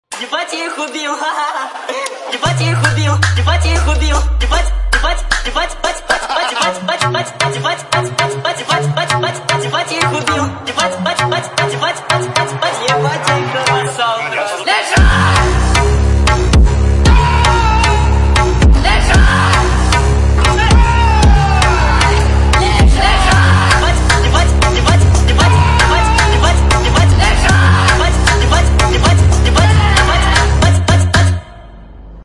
donk